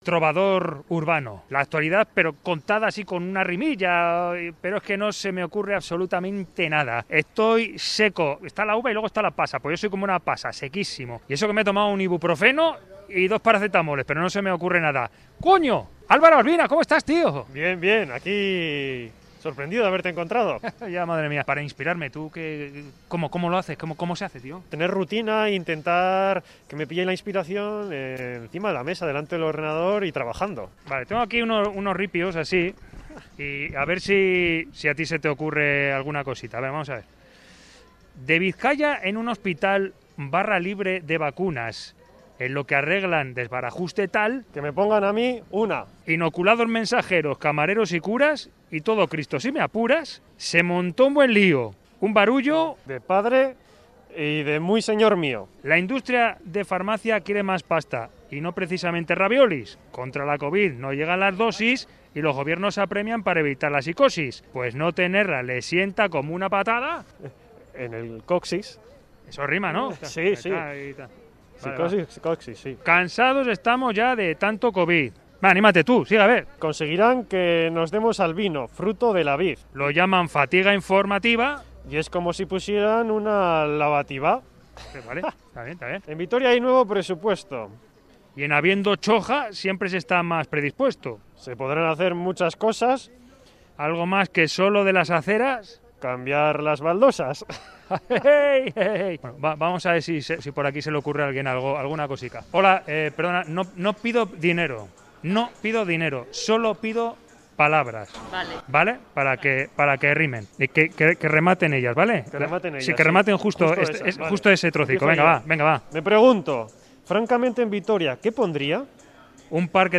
Salimos a la calle para contar la actualidad de Vitoria en verso
Audio: Unas rimas jocosas para plasmar lo que pasa en Vitoria con ayuda de las y los vitorianos.